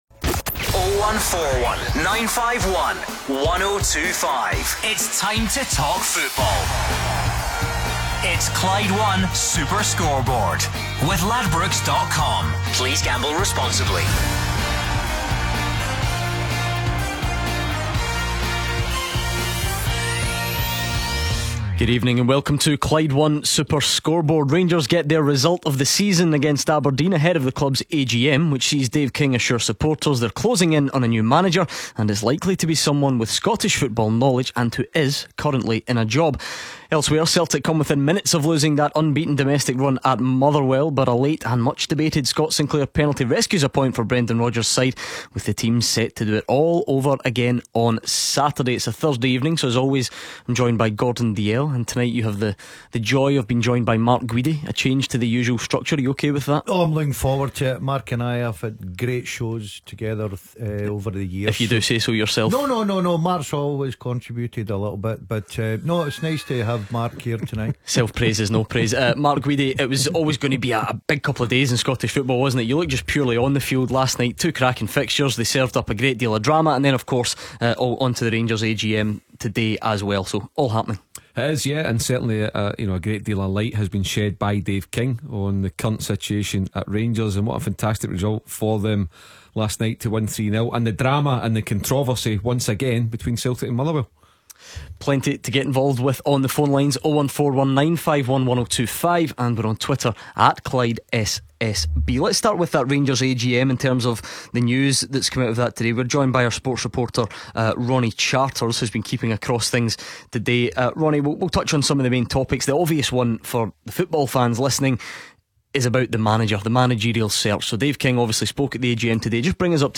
take your calls...